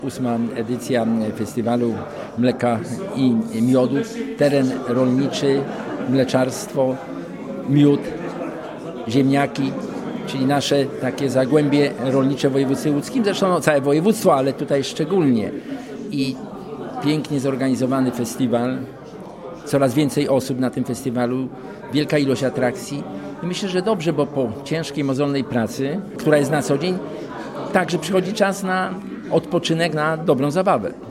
Festiwal Mleka i Miodu w Strumianach
wojewoda-lodzki-w-Burzeninie.mp3